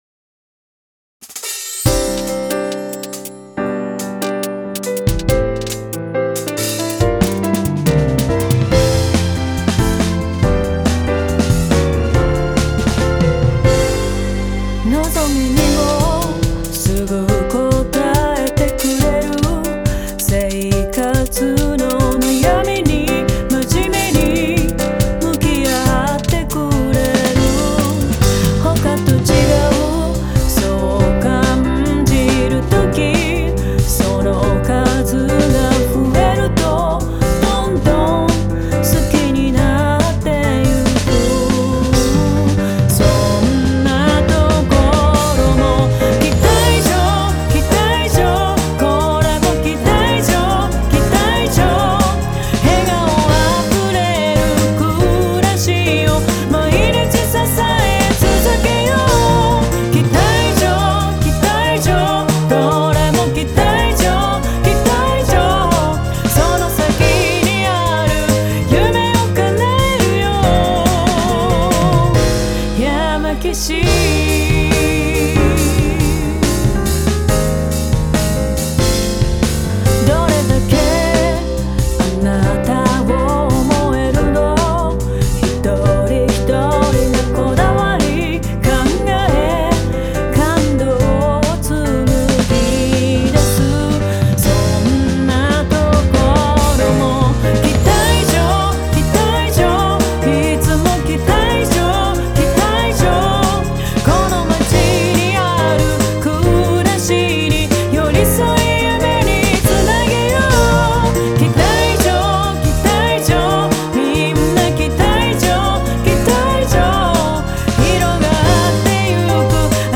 歌唱サンプル